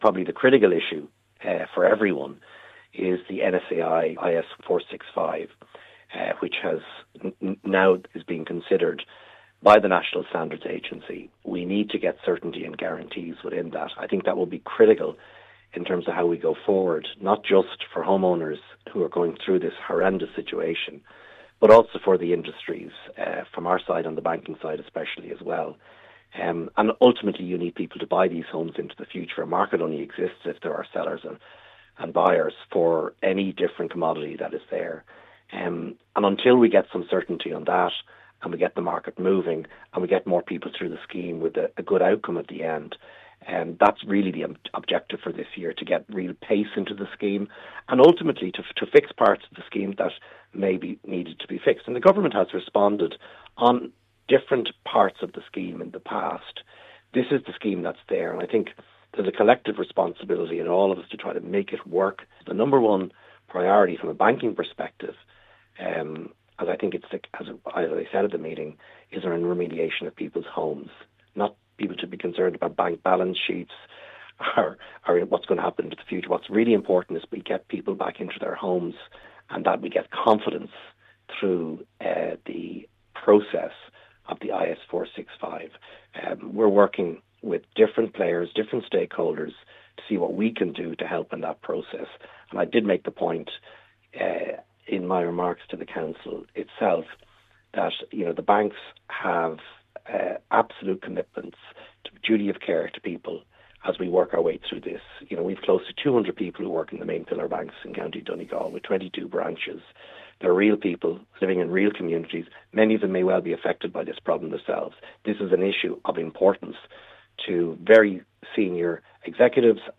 My Hayes told Highland Radio News it’s vital that the market can start to move again………..